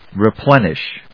音節re・plen・ish 発音記号・読み方
/rɪplénɪʃ(米国英語), ri:ˈplenɪʃ(英国英語)/